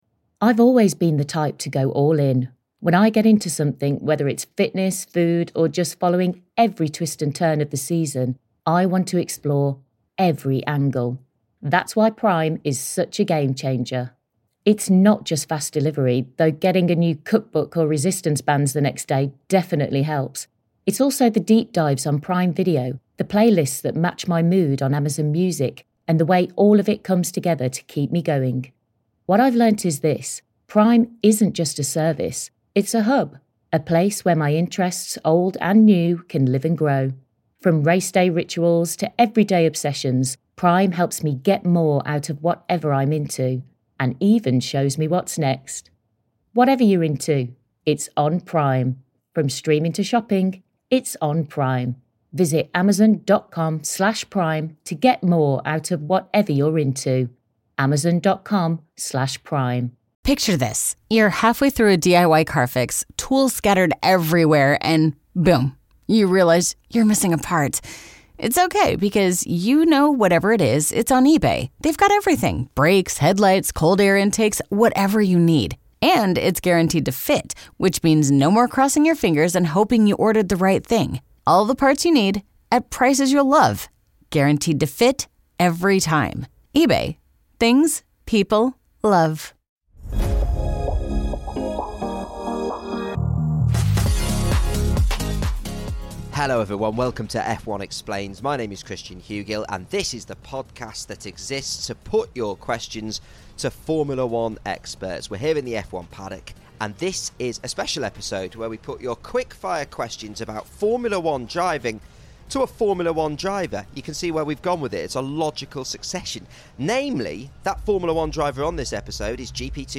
Overtaking, braking and qualifying - Stoffel Vandoorne answers your questions